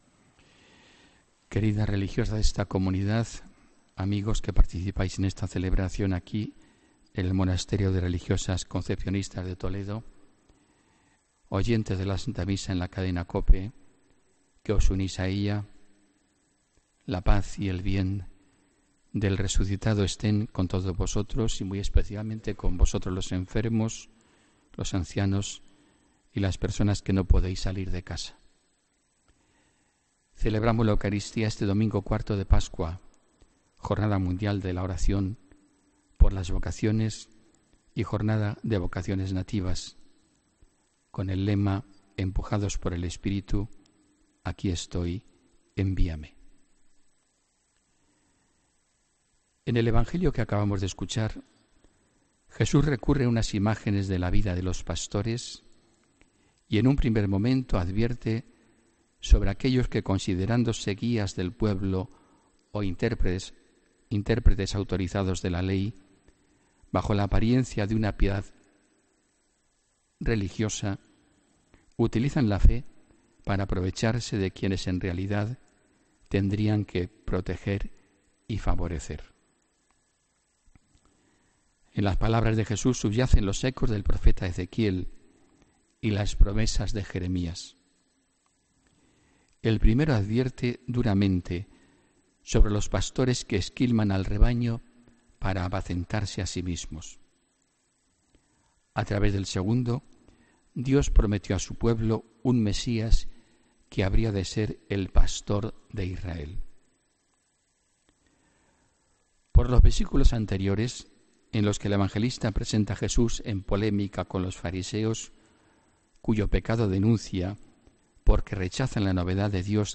Homilía del domingo 7 de mayo de 2017